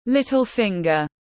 Ngón út tiếng anh gọi là little finger, phiên âm tiếng anh đọc là /ˌlɪt.əl ˈfɪŋ.ɡər/.
Little finger /ˌlɪt.əl ˈfɪŋ.ɡər/